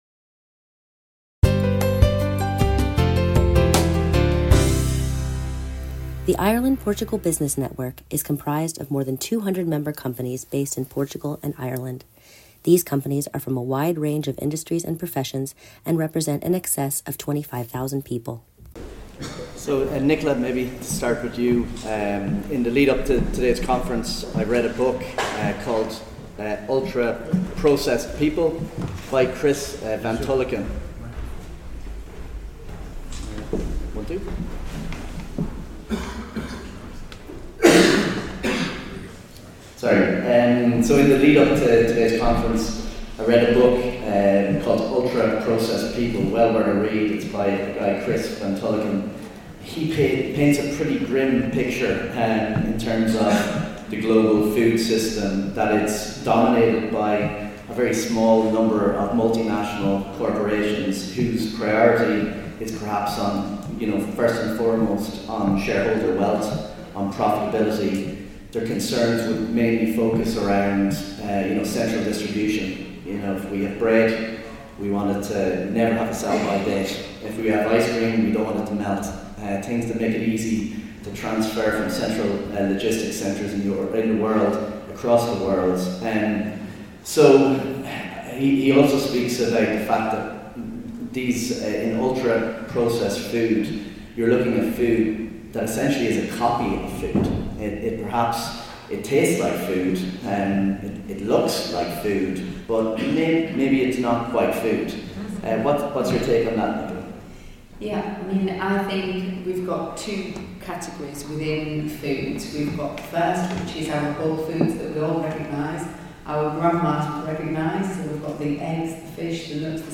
Ireland Portugal Business Network Podcast series - IPBN Podcast Series ep.36 - The Food & Nutrition Panel Discussion